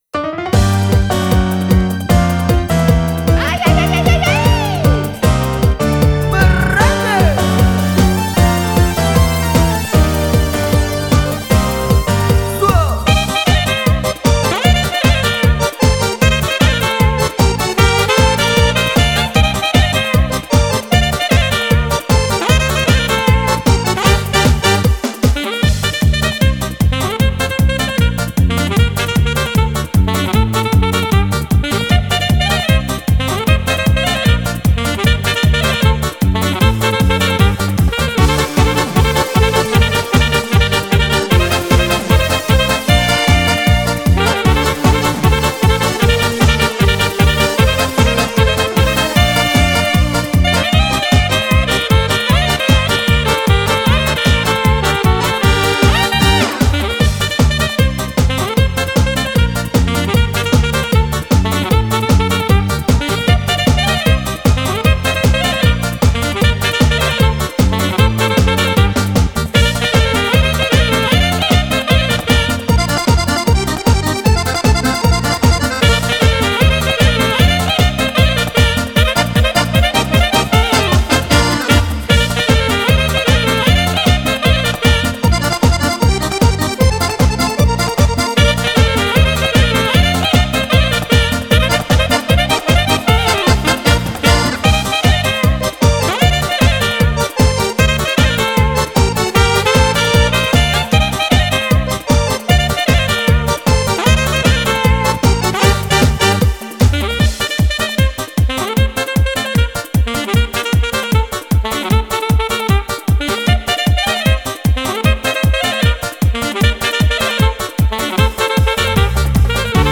12 ballabili per sax solista